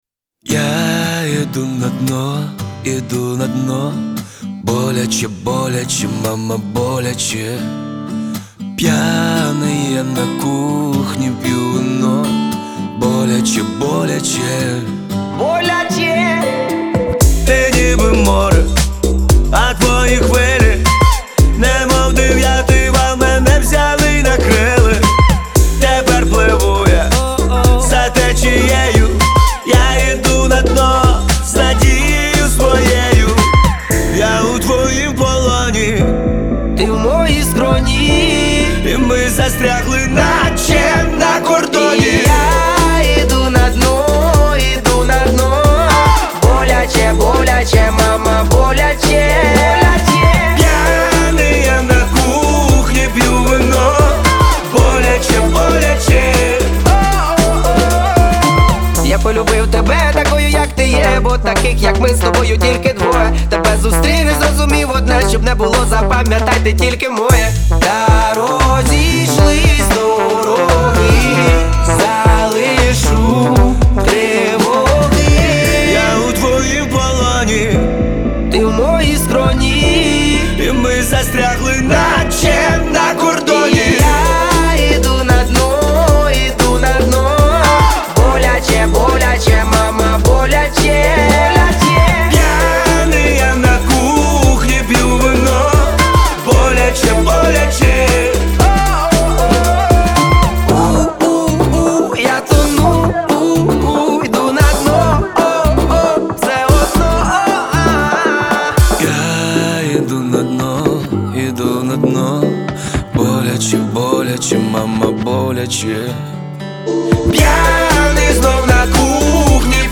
• Жанр: Pop, Hip-Hop